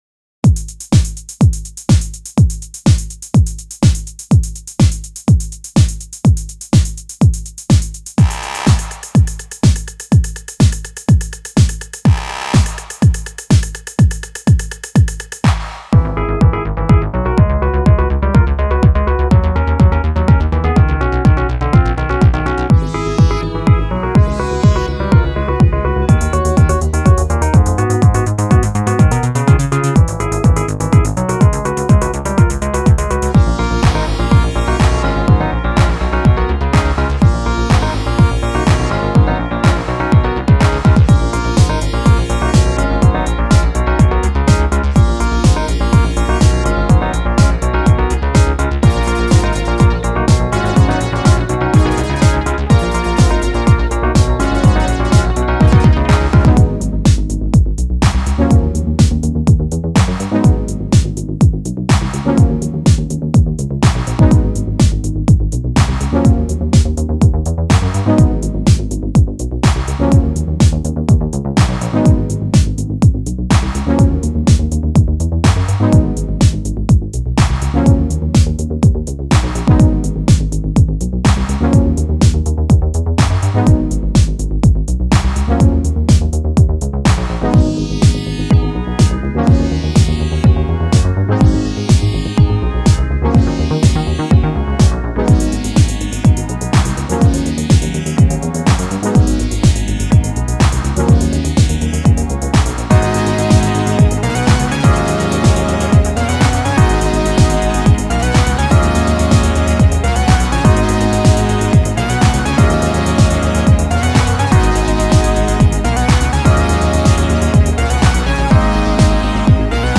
strings